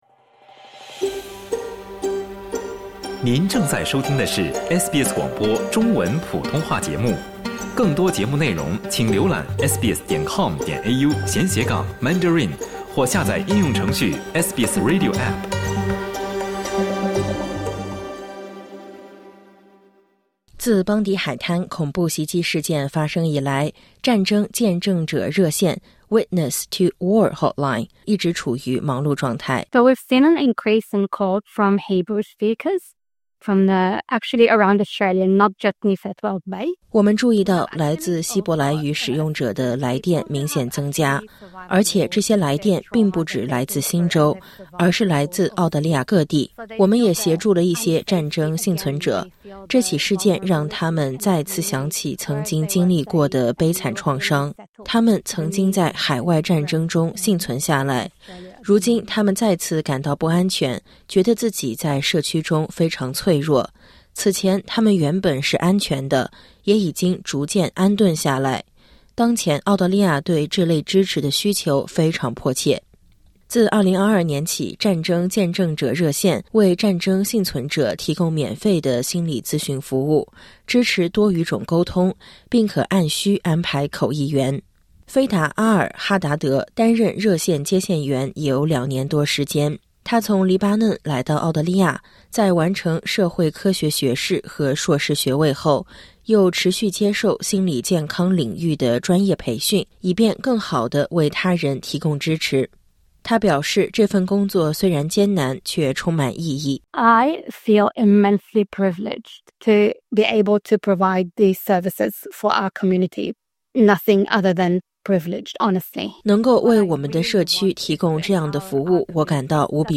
负责运营这条热线的新州机构表示，这笔资金将用于把服务范围扩展至全国，协助难民应对复杂创伤，以及邦迪事件所引发的长期心理影响。点击音频，收听完整报道。（音频内容可能会令部分听众感到不安，敬请留意。）